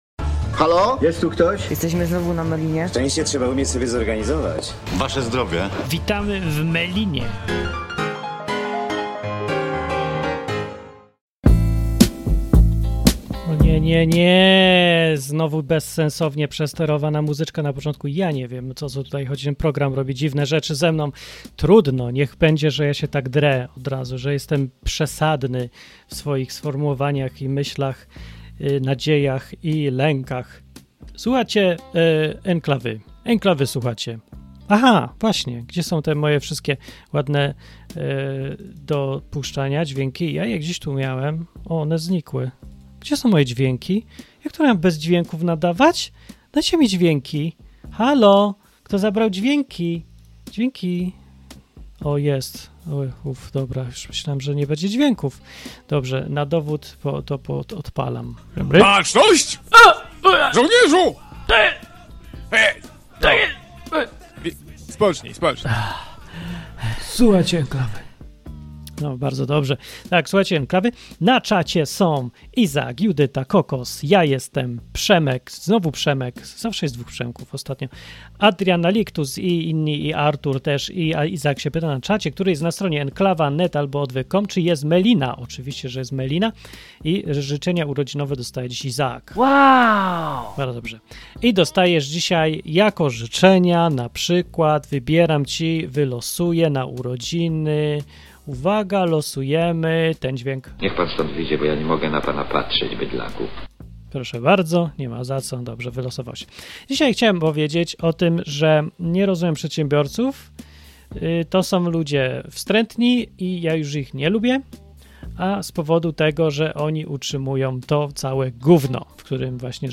Melina w radiu Enklawa to poniedziałkowa audycja na żywo. Rozmowy ze słuchaczami o wolności, o życiu, o społeczeństwie, o Bogu, o relacjach i o tym co kogo gryzie albo cieszy.